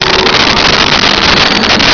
sfx_pod_ani_shift4.wav